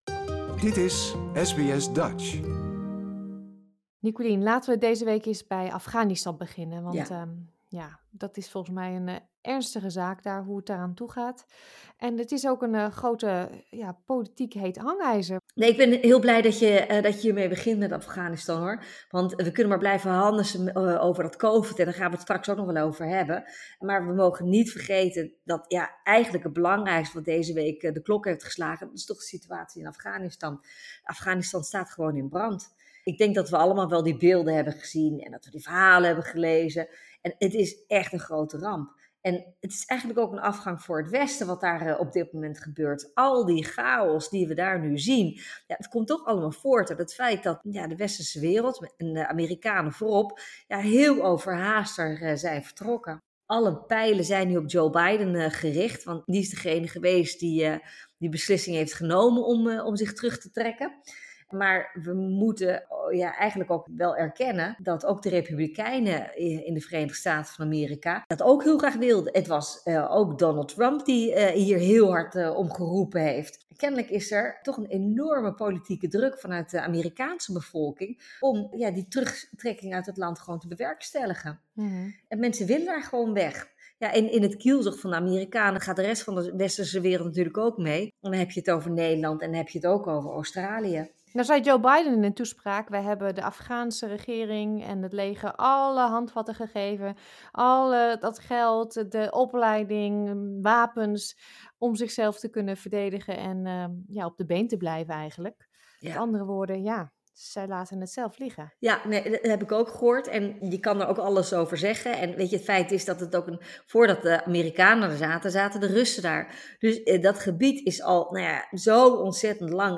In onze wekelijkse politieke beschouwing met Nicolien van Vroonhoven bespreken we de situatie in Afghanistan en hoe de internationale politiek daarmee omgaat, we vragen ons af hoe het gaat met de kabinetsformatie in Nederland en praten over de COVID-19 chaos in Australië.